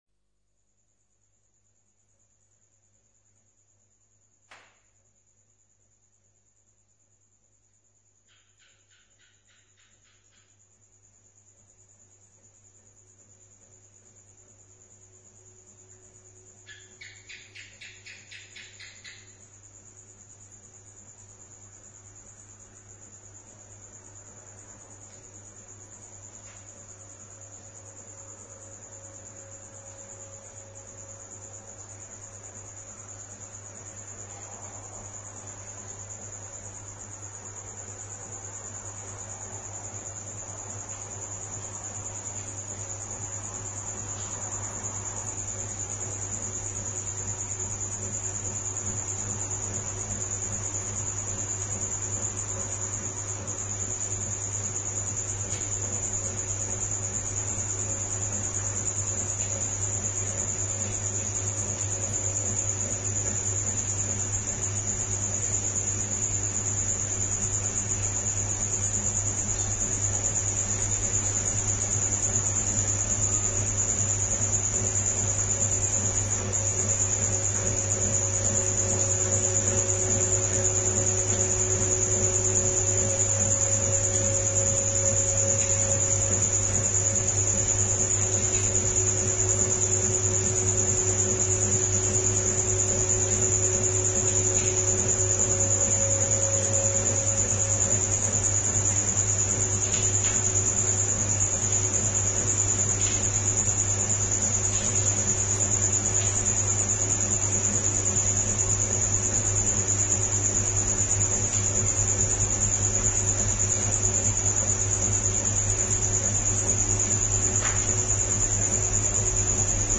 Recorded outside Katherine, Northern Territory, Australia, 2005 |
On the fringes of a town of 8.000, on the edge of Australia’s tropical north with the desert some 200kms south, the heat and humidity that goes with, living in a 12×9 metres shed means silence is something you never really hear. Crickets, cicadas, geckos, bats, passing cars and of course the wavering overtones from the ceiling fans keep the air alive in the „house“ as the outside comes in.